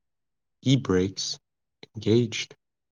e-brakes-engaged.wav